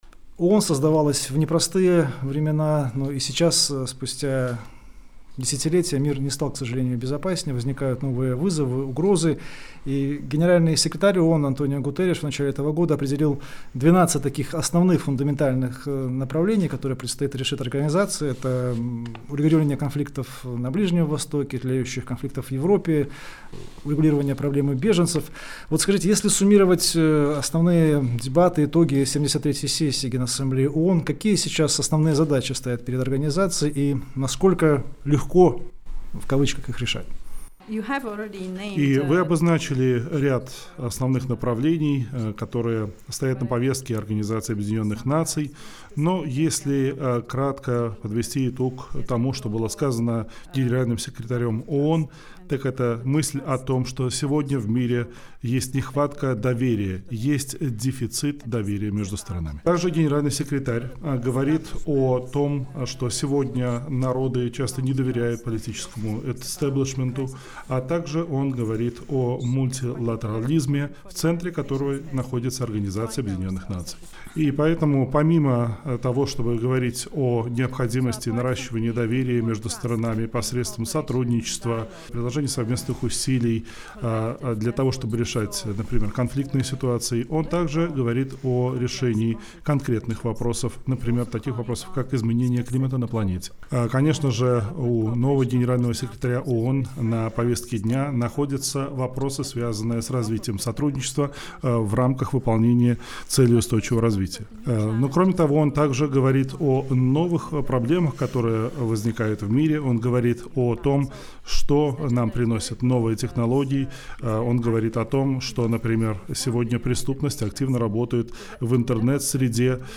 Интервью с Постоянным координатором ООН в Беларуси Иоанной Казаной-Вишневецкий | Радио Беларусь